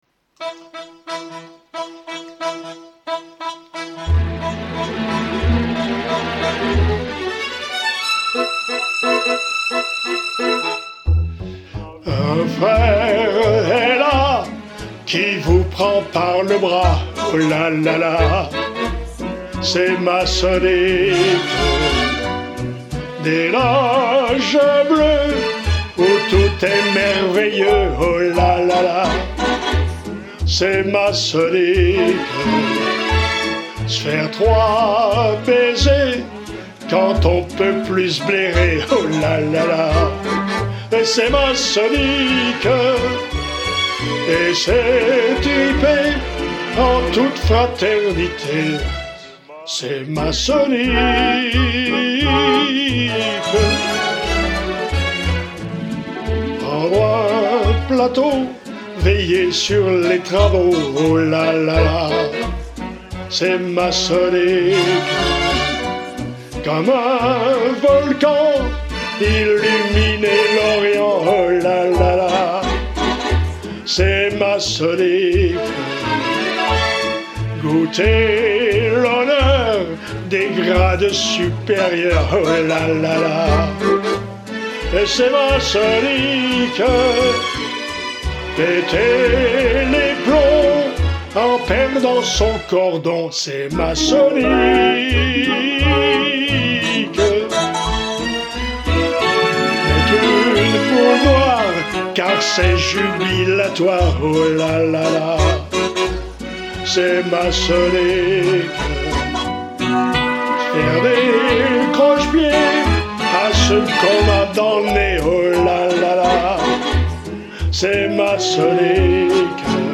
Version solo